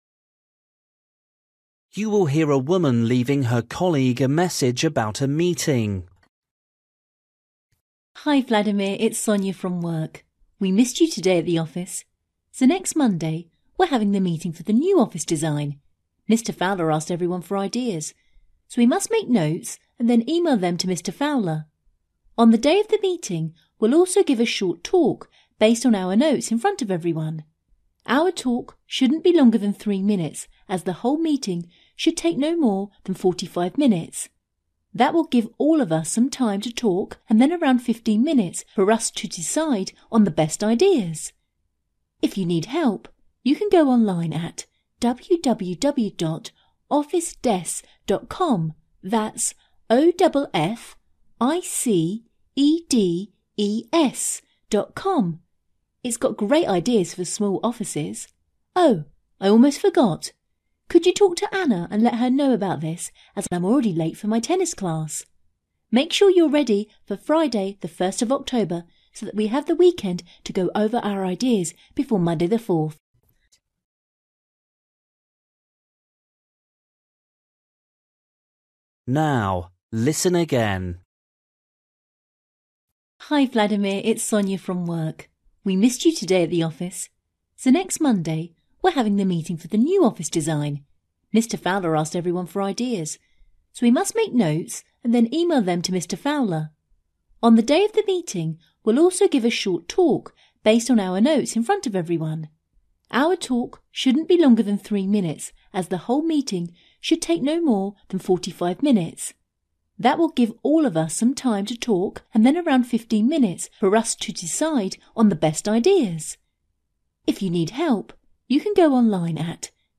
You will hear a woman leaving her colleague a message about a meeting.